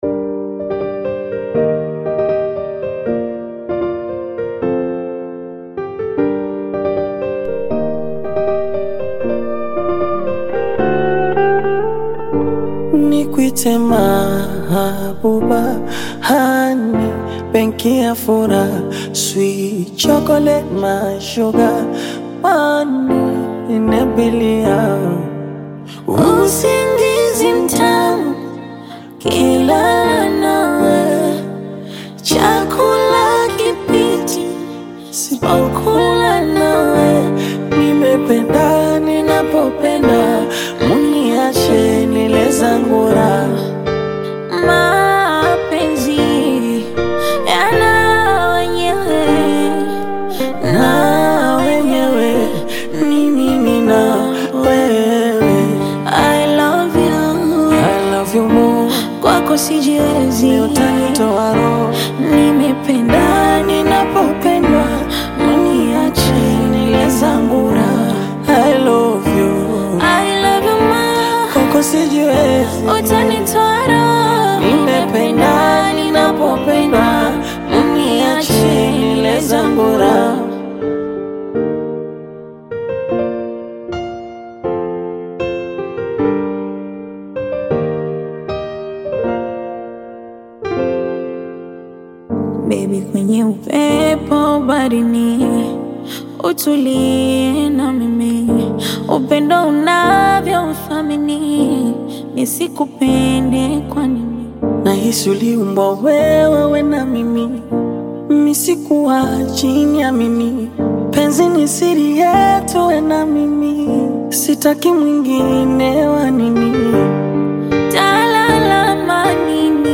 a smooth Afro-Pop/Bongo Flava love track